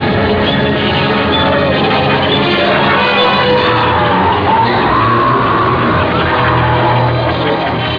Ripley can hear Lambert's desperate fear-filled cries, she softly calls to them on the comm system but they don't hear her.